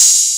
Metro Open Hat.wav